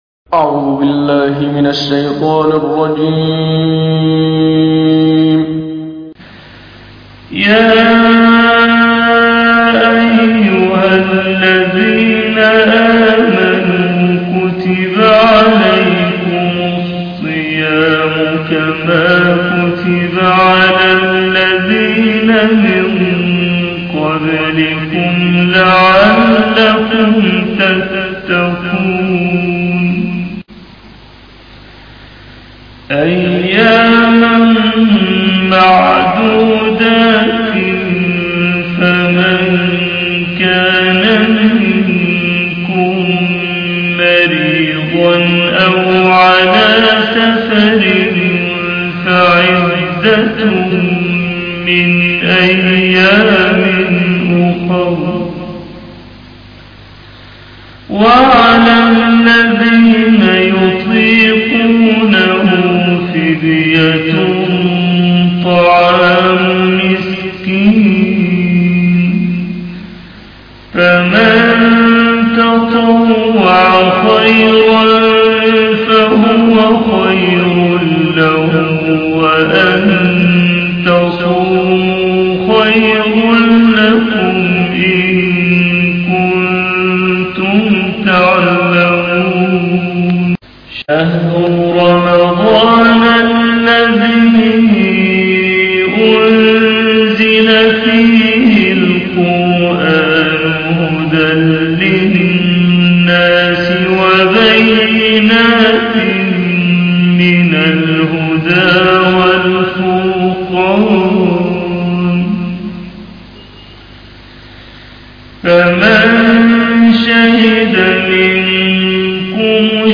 القرآن الكريم وعلومه     التجويد و أحكام التلاوة وشروح المتون